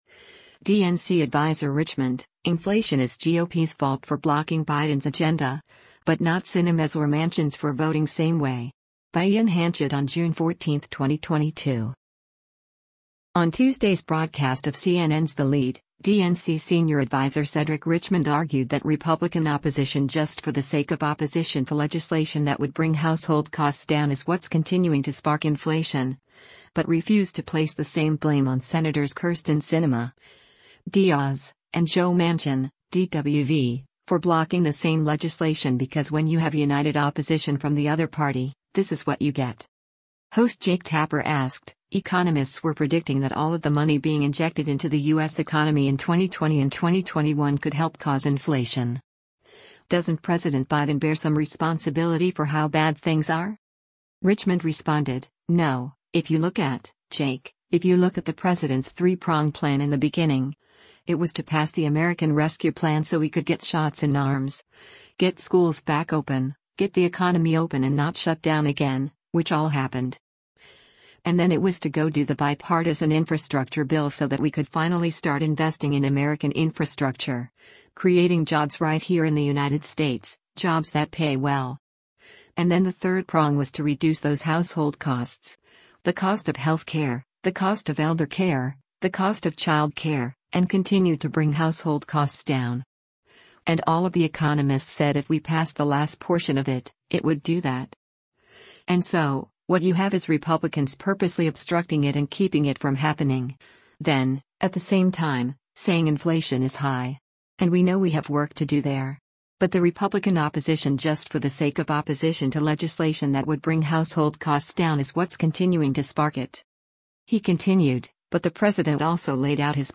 On Tuesday’s broadcast of CNN’s “The Lead,” DNC Senior Adviser Cedric Richmond argued that “Republican opposition just for the sake of opposition to legislation that would bring household costs down is what’s continuing to spark” inflation, but refused to place the same blame on Sens. Kyrsten Sinema (D-AZ) and Joe Manchin (D-WV) for blocking the same legislation because “when you have united opposition from the other party, this is what you get.”